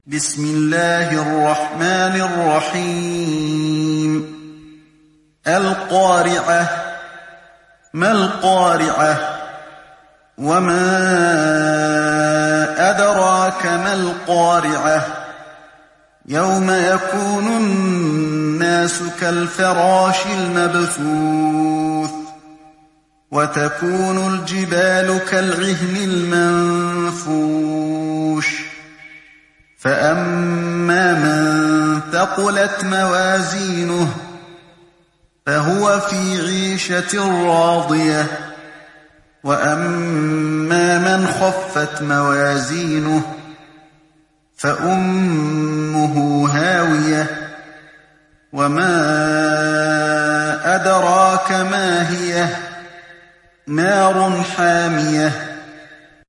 تحميل سورة القارعة mp3 بصوت علي الحذيفي برواية حفص عن عاصم, تحميل استماع القرآن الكريم على الجوال mp3 كاملا بروابط مباشرة وسريعة